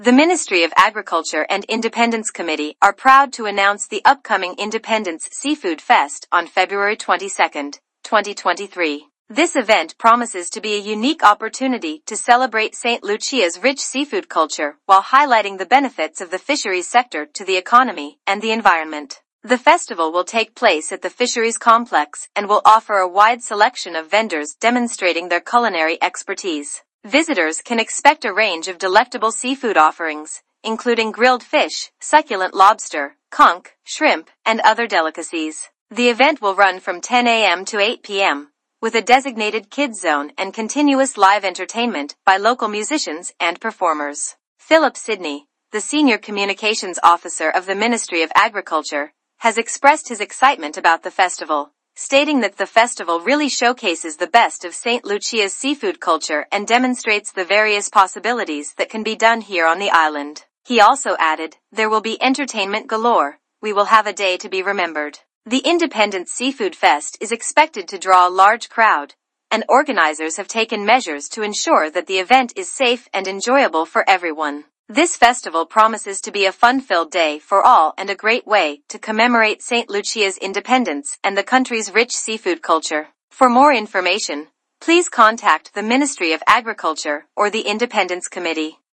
Play Press Release